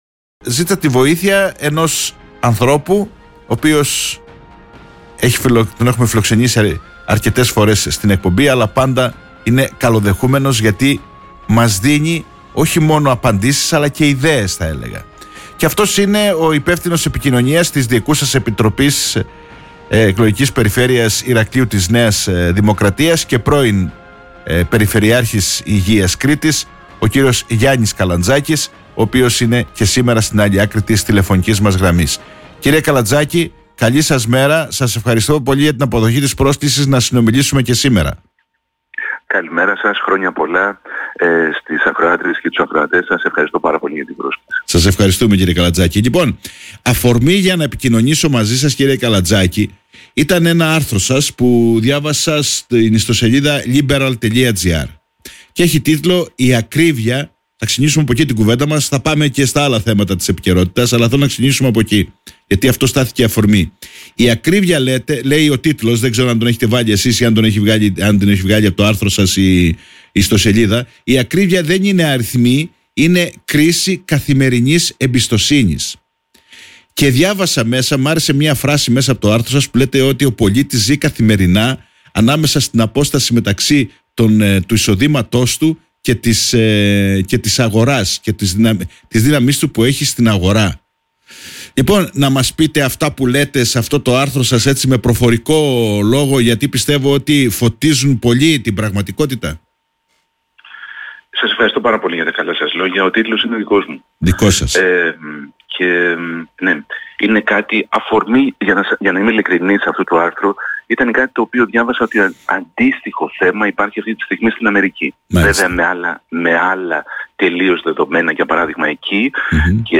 μιλάει στην εκπομπή “Όμορφη Μέρα”